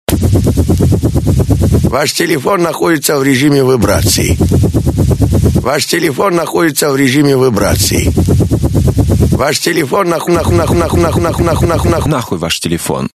Вибро